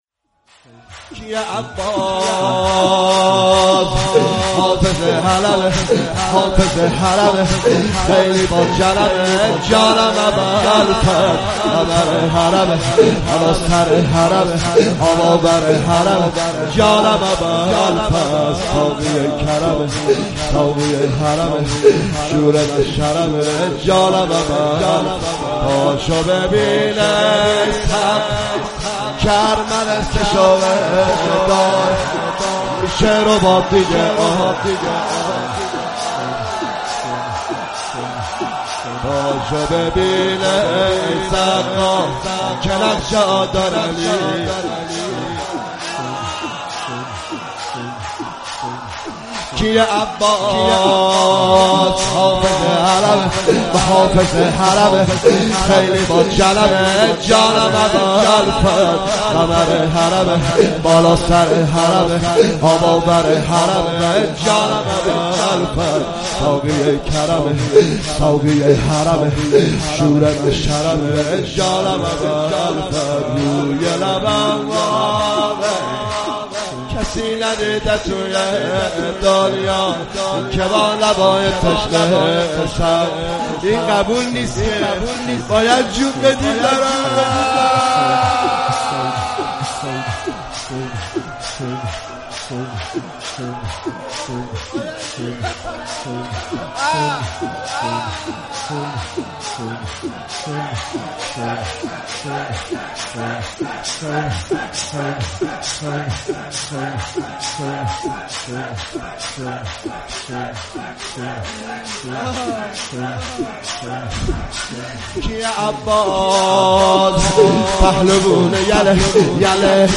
دو دمه